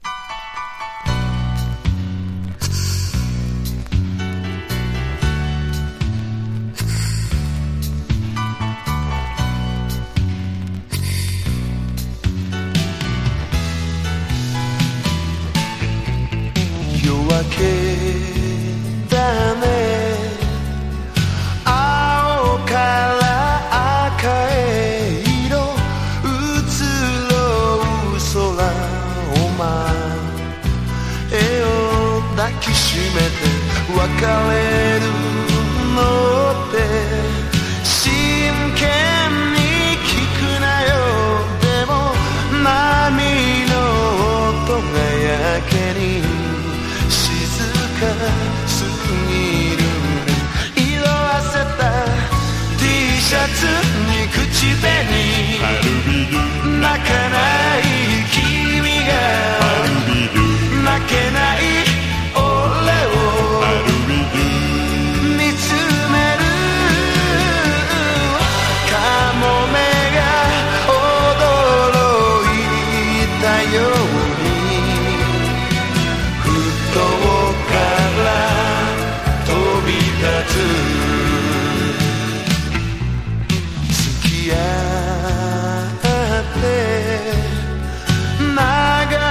CITY POP / AOR